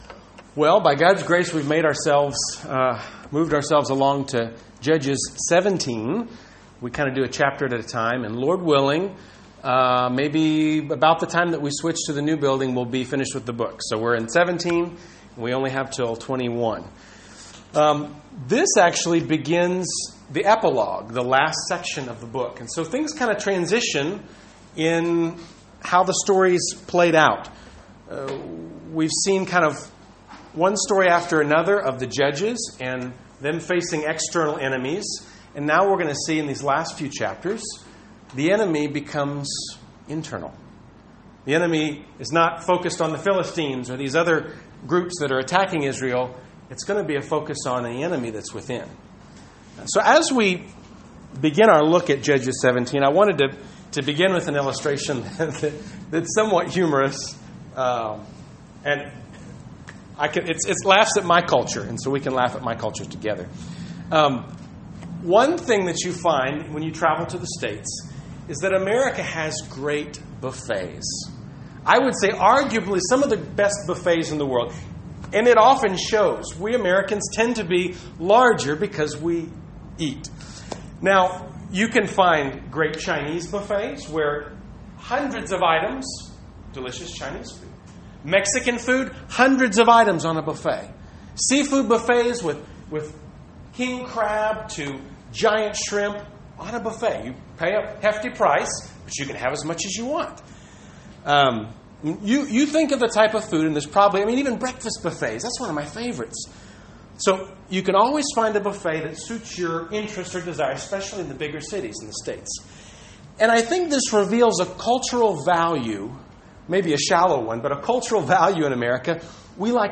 Bible Text: Judges 17 | Predicatore/Preacher